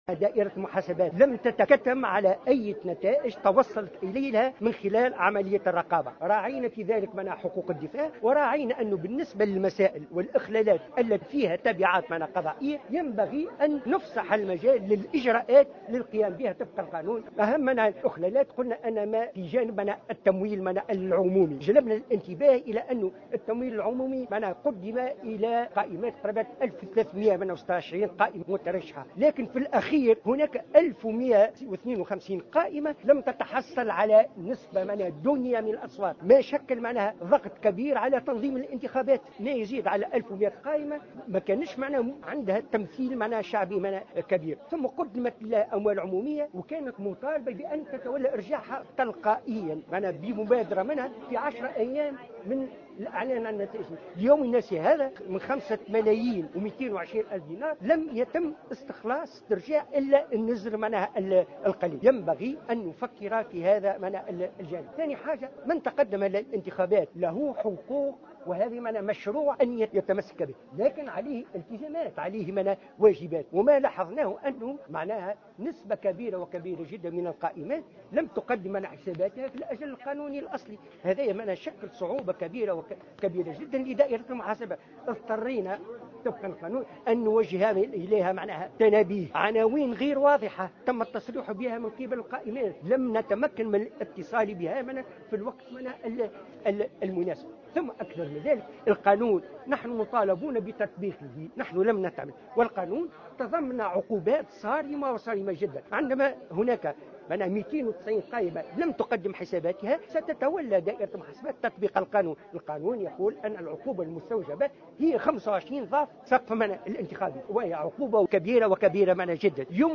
خلال ندوة صحفية عقدت اليوم الجمعة 19 جوان 2015 بالعاصمة